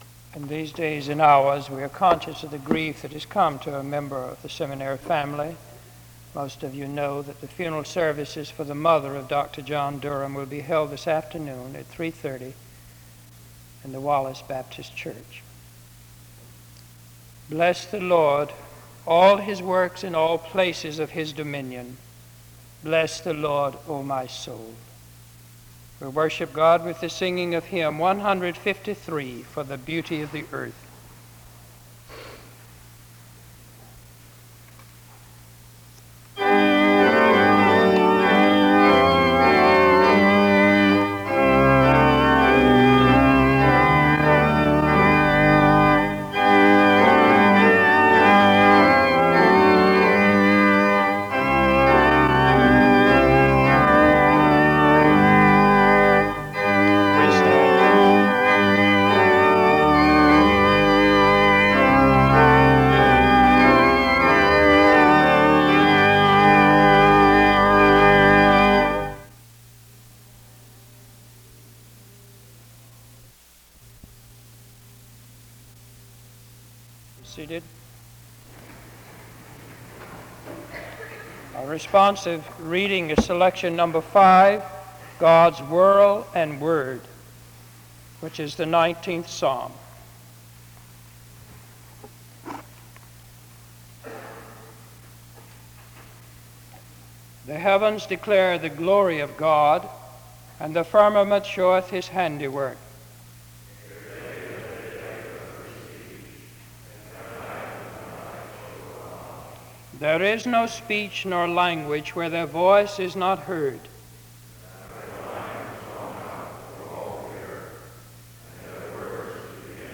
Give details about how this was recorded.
The service opens with an announcement and song from 0:00-1:08. A responsive reading takes place from 1:20-3:30. A prayer is offered from 3:32-6:04.